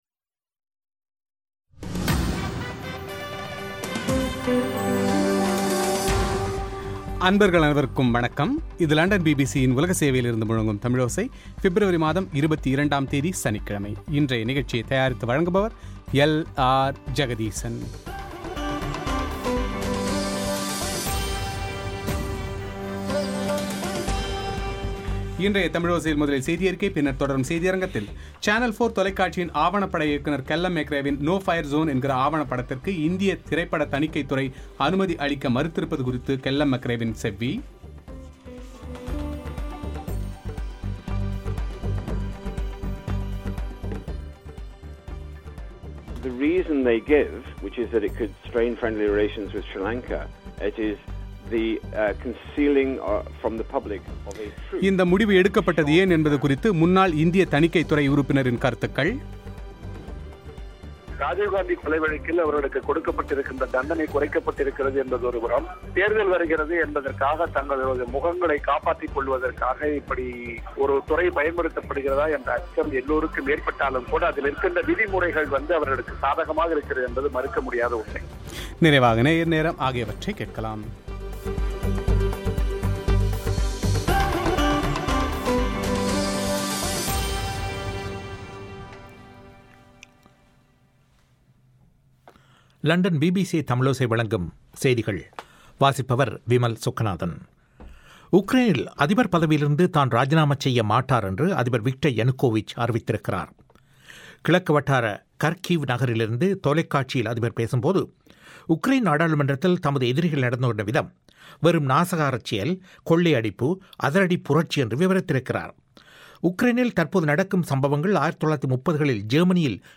சேனல் 4 தொலைக்காட்சியின் ஆவணப்பட இயக்குநர் கெல்லம் மெக்ரேவின் நோ பயர் சோன் என்கிற ஆவணப்படத்துக்கு இந்திய தணிக்கைத்துறை அனுமதி அளிக்க மறுத்திருப்பது குறித்து கெல்லம் மெக்ரேவின் செவ்வி;